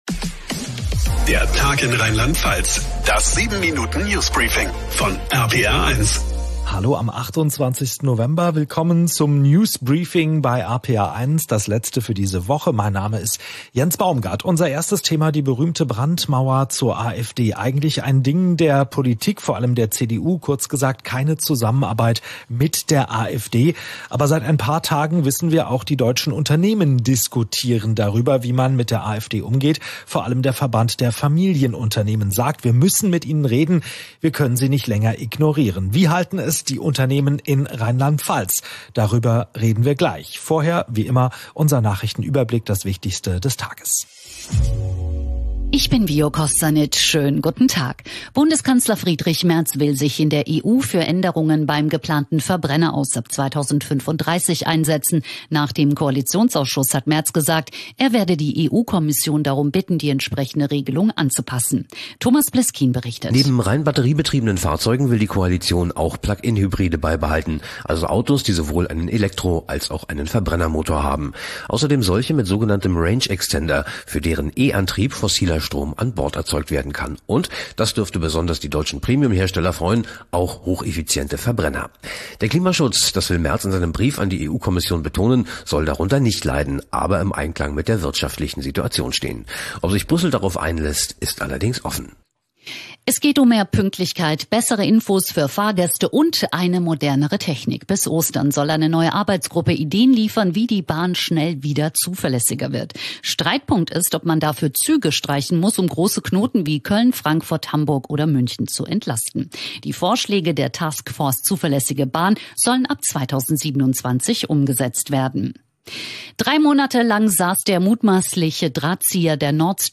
Jeden Tag neu um 16:30 Uhr: Dein täglicher News-Podcast von RPR1.